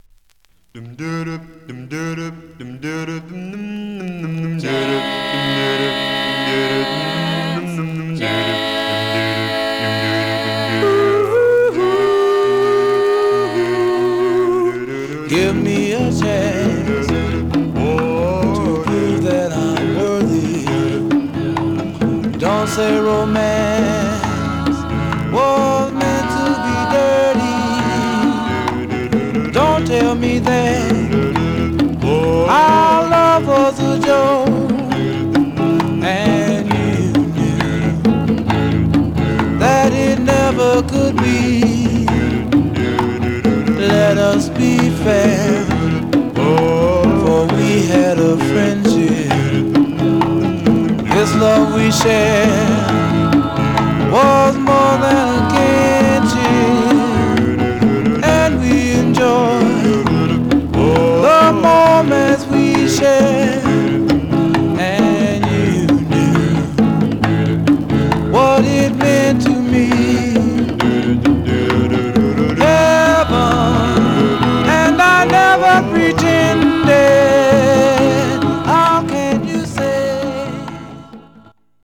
Surface noise/wear
Mono
Male Black Group